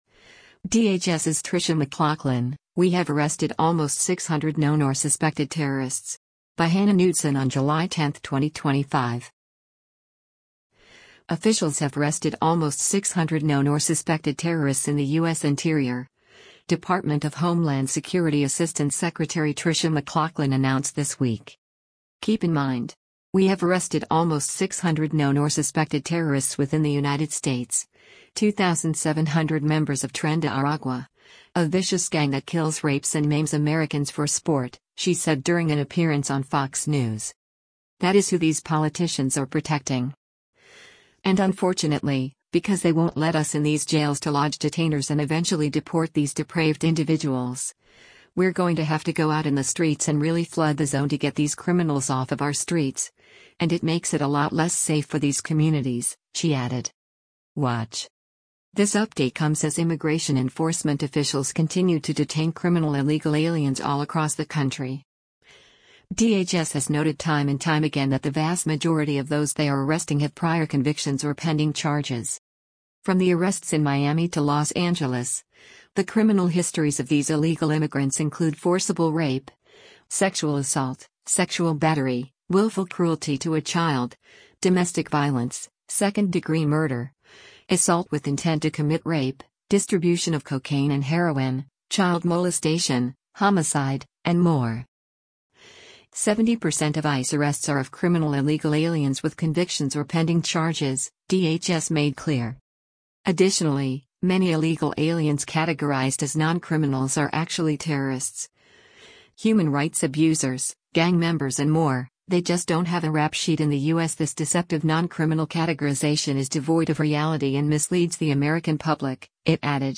“Keep in mind… we have arrested almost 600 known or suspected terrorists within the United States — 2,700 members of Tren de Aragua, a vicious gang that kills rapes and maims Americans for sport,” she said during an appearance on Fox News.